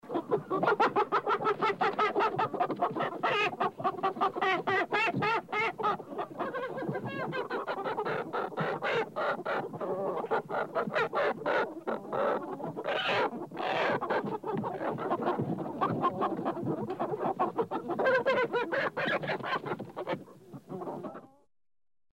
Звуки курятника
На этой странице собраны разнообразные звуки курятника: от кудахтанья кур до петушиных криков на рассвете.